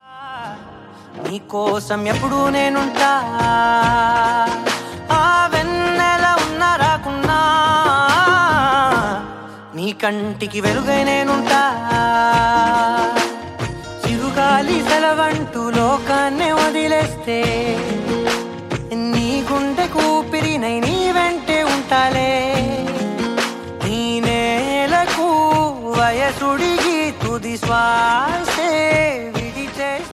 love song ringtone
dance ringtone download